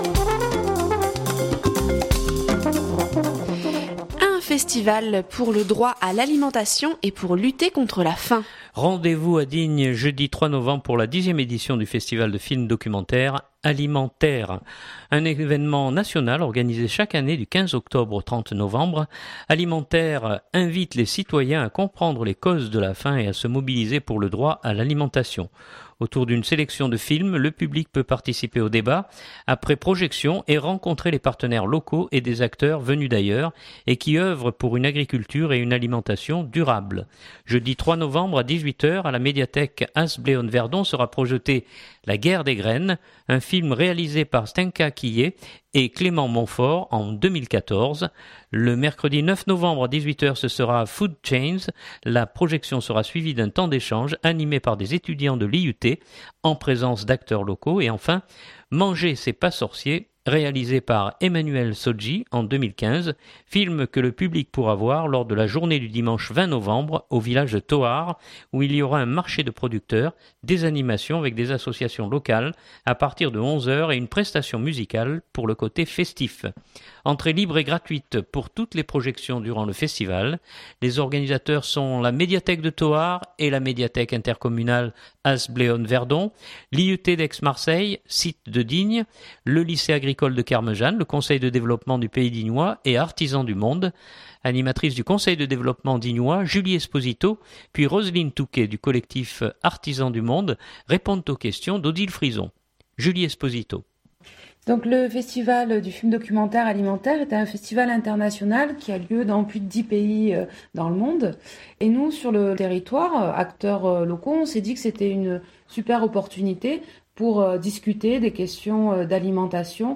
répondent aux questions